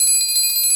Perc (4).wav